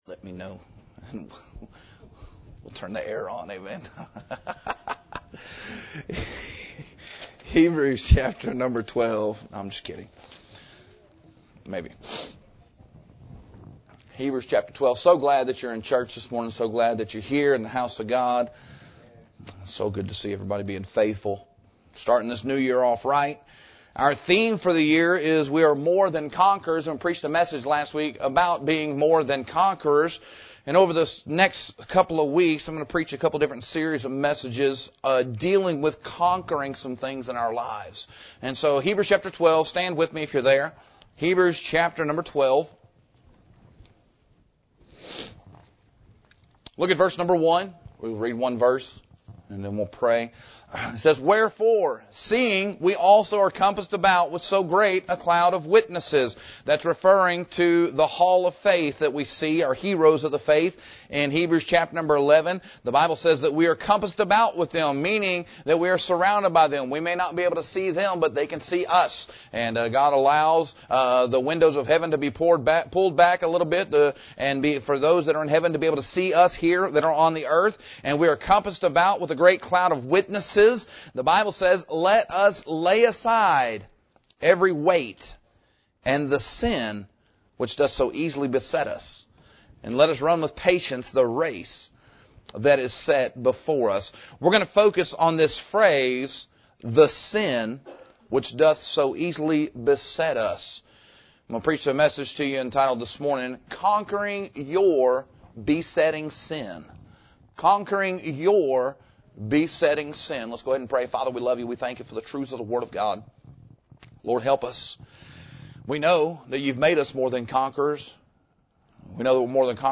The apostle urges believers to lay aside both unnecessary weights and the specific sin that constantly knocks us off course. This sermon unpacks this passage and gives a clear, biblical pathway—recognition, repentance, replacement, repetition, and request—so you can press on in victory through Christ.